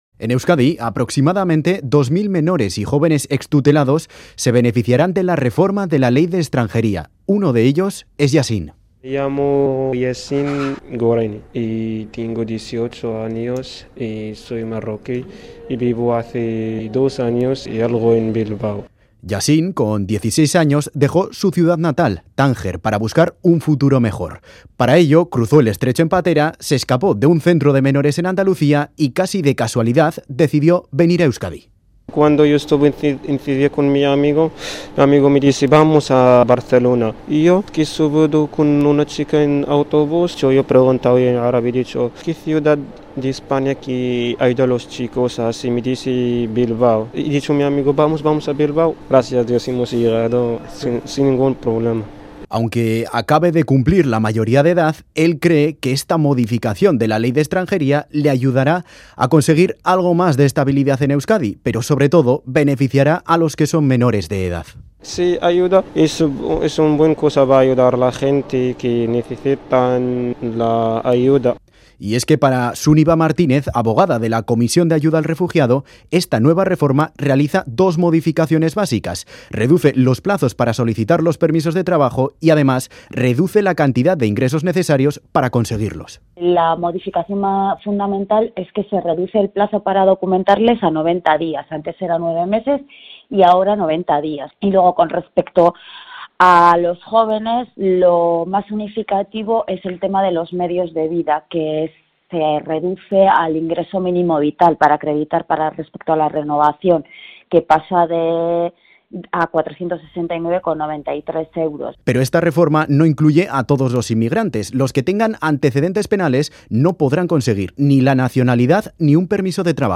Audio: Este martes ha entrado en vigor la modificación de la Ley de Extranjería para facilitar a los menores y jóvenes extranjeros no acompañados conseguir los papeles. Analizamos las modificaciones con un joven magrebí